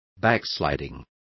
Also find out how recaida is pronounced correctly.